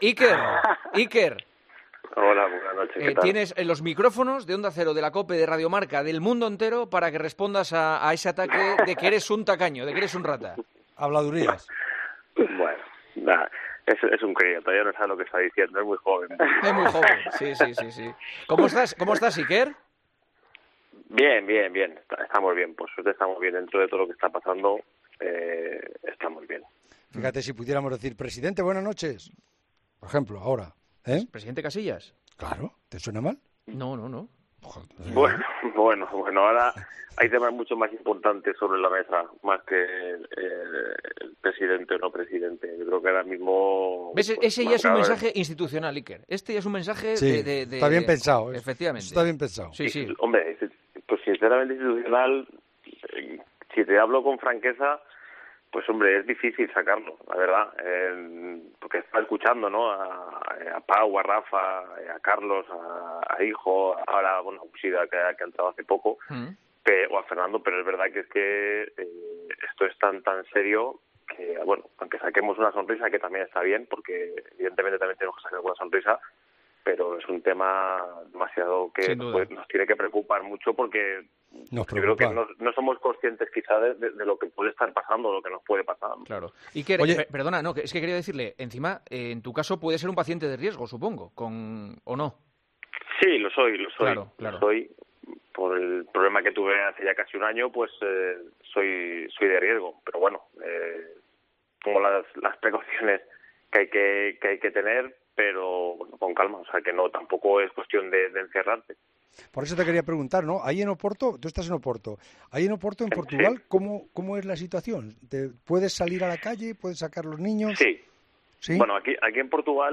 AUDIO - ENTREVISTA A IKER CASILLAS, EN EL PARTIDAZO DE COPE Y EL TRANSISTOR DE ONDA CERO